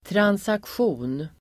Ladda ner uttalet
transaktion substantiv, transaction Uttal: [transaksj'o:n] Böjningar: transaktionen, transaktioner Synonymer: överföring Definition: affärsuppgörelse; manipulation Exempel: ekonomiska transaktioner (monetary transactions)